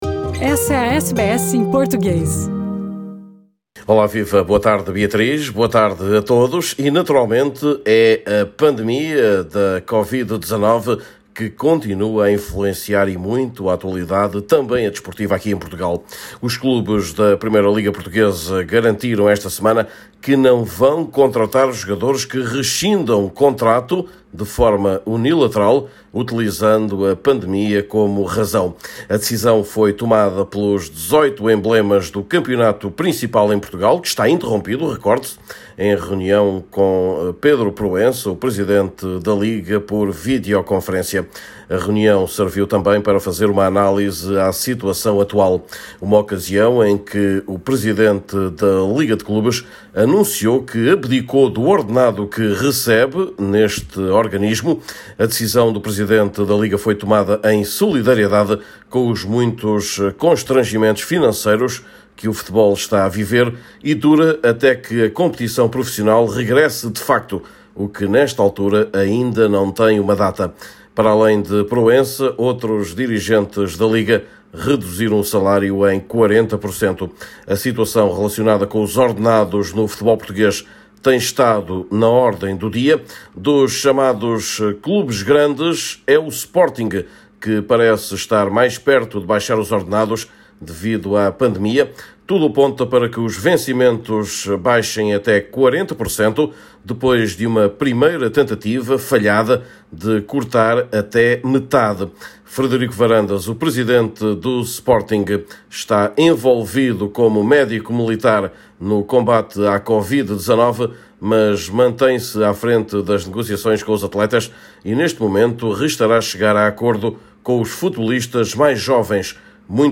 Neste boletim semanal